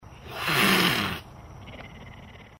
Хорошие варианты звуков рычания и шипения дикой пумы в mp3 формате.
11. Шипнула разок
puma-shypit.mp3